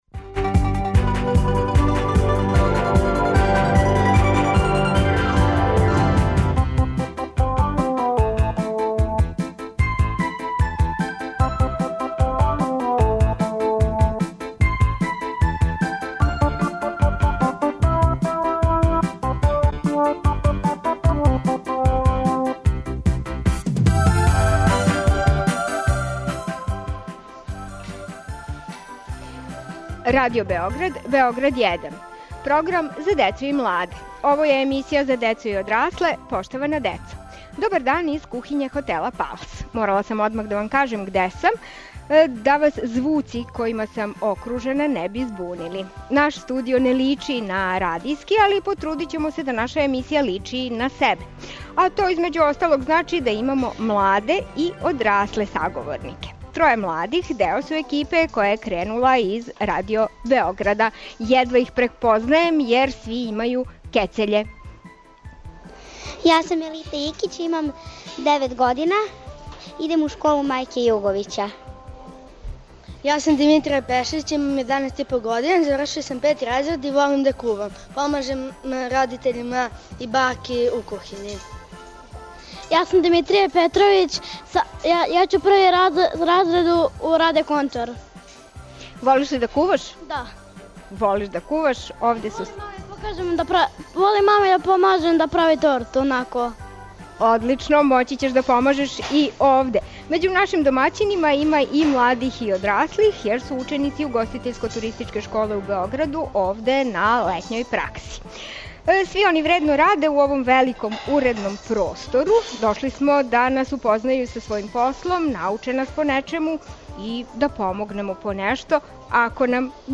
Гостујемо кухињи хотела “Палас”. Чућете са лица места како се мали људи интересују за посао великих.